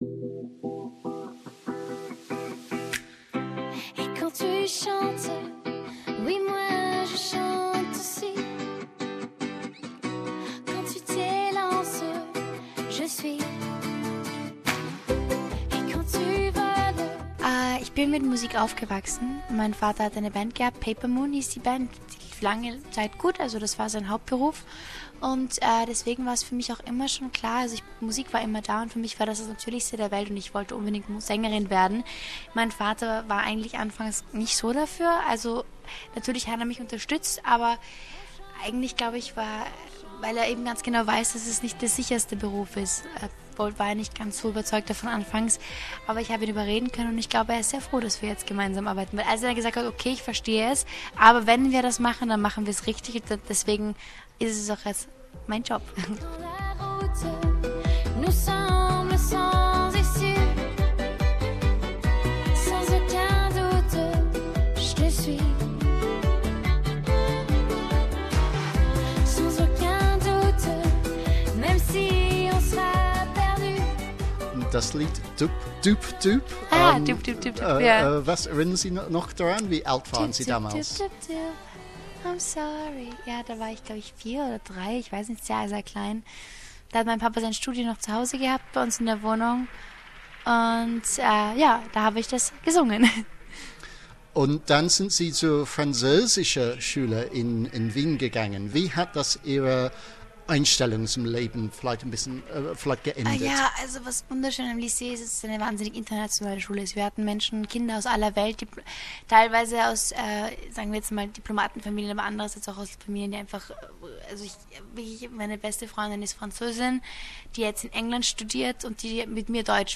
Die 19-jährige singt beim Eurovision Song Contest den österreichischen Beitrag - auf Französisch.